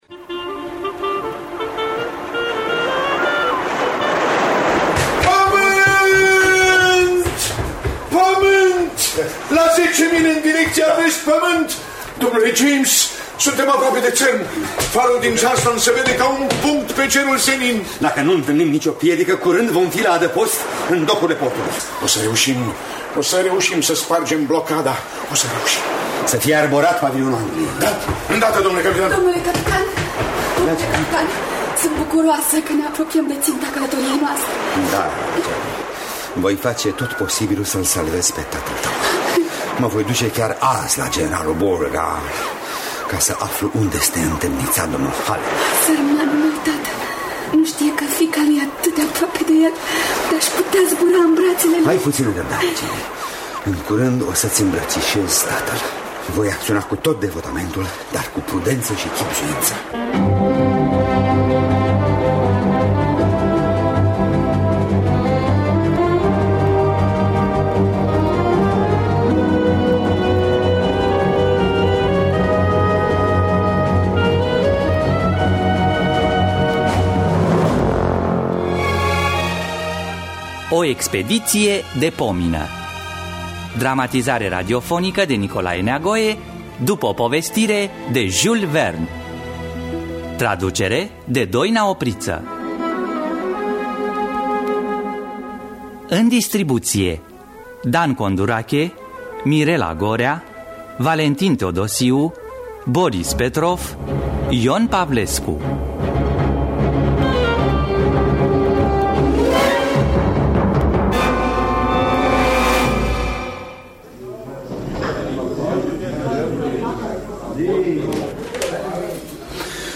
Dramatizarea radiofonică
Înregistrare din anul 1995 (25 noiembrie).